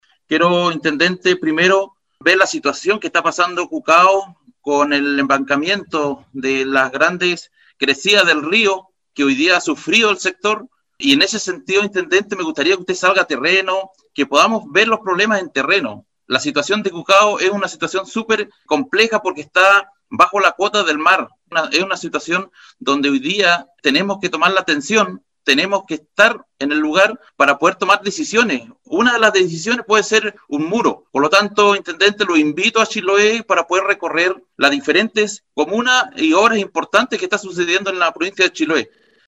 El Consejero Miranda, añadió que una de las soluciones para resolver el embancamiento que genera el rio en el sector de Cucao, puede ser la construcción de un muro de contención, para lo cual es necesario elaborar un proyecto para este tipo de obras: